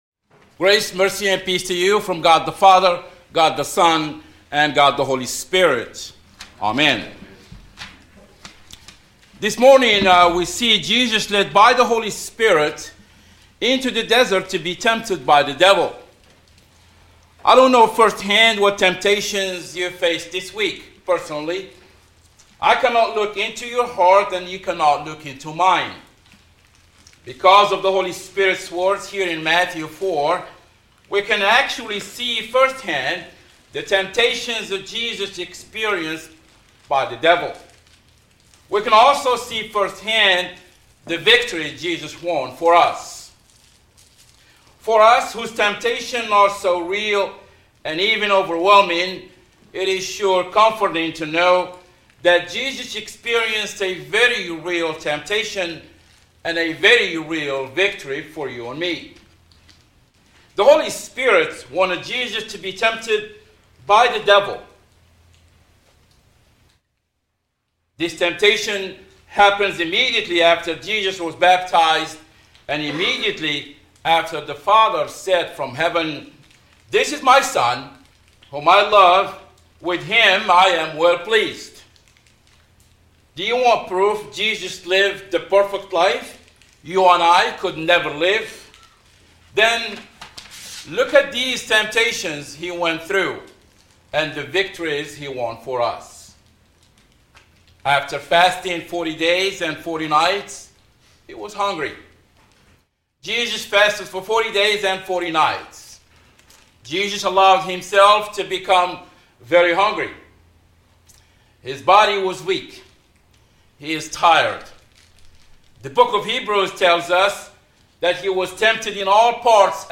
2011 Sermons